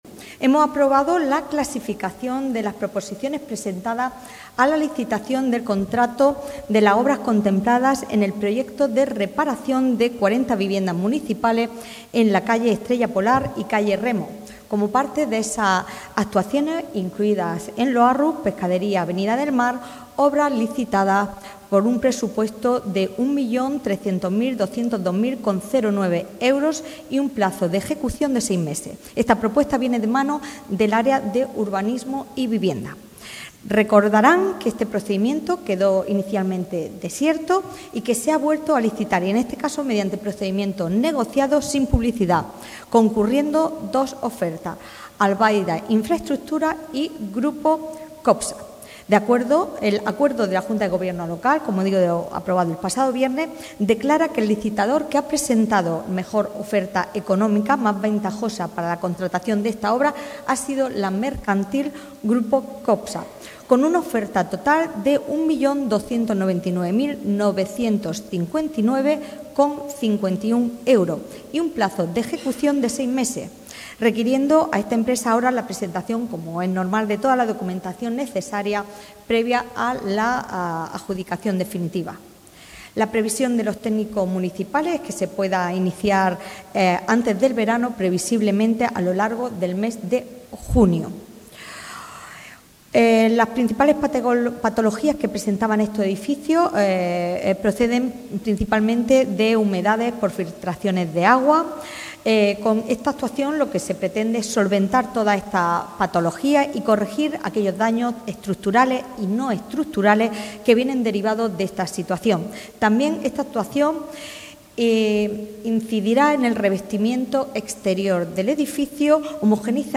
Así lo ha trasladado la portavoz del Equipo de Gobierno, Sacramento Sánchez, informando esta mañana en rueda de prensa del contenido de los acuerdos adoptados en Junta de Gobierno Local, entre los que se incluyeron la aprobación de la clasificación de proposiciones presentadas a la licitación de este proyecto, avanzando así en la adjudicación del mismo.